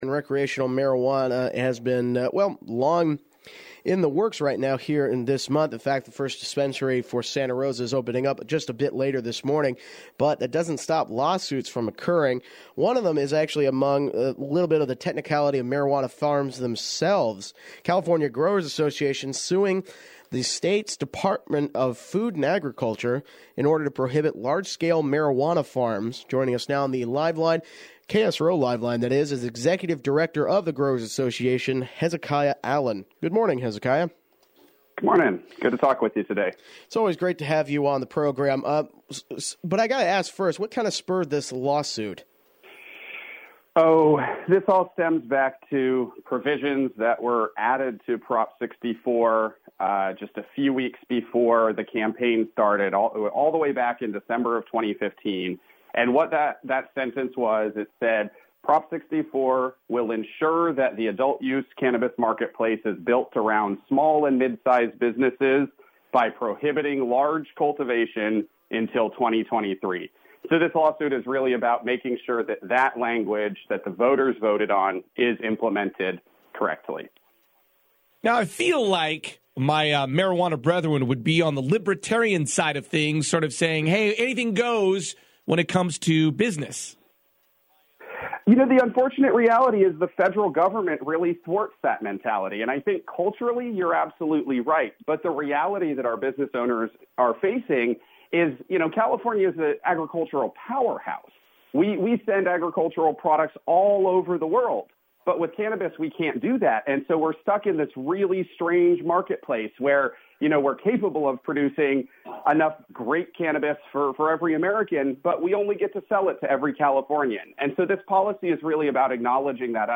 Interview: Increase of Marijuana Prices Due to Taxes